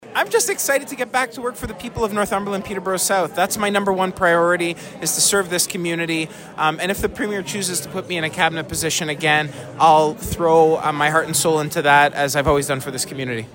We asked Piccini what the next four years and beyond might hold for him.